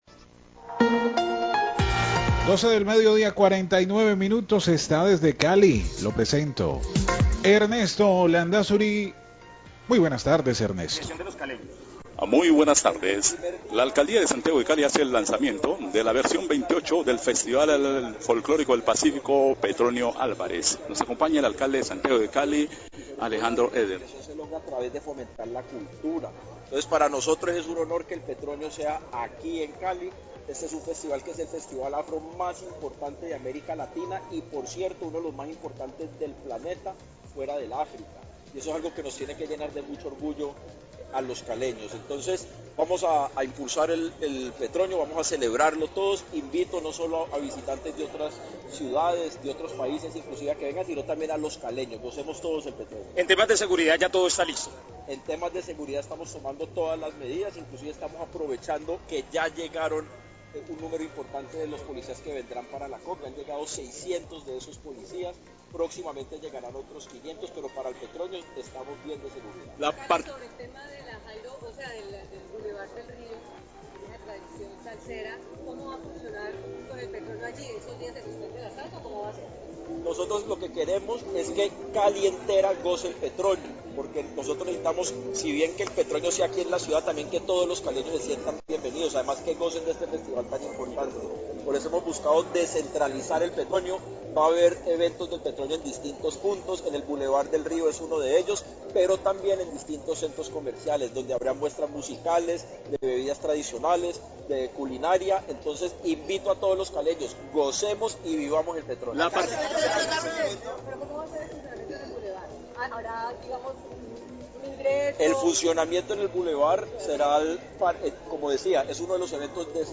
Alcalde de Cali, Alejandro Eder, habló con los medios en el lanzamiento del Festival Petronio Álvarez. Se refirió a la descentralización que tendrá el festival este año y a la participación internacional de artistas, turistas y periodistas.